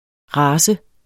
race 1 substantiv, fælleskøn Bøjning -n, -r, -rne Udtale [ ˈʁɑːsə ] Oprindelse via fransk race fra italiensk razza 'art, slags; race', af uvis oprindelse Betydninger 1.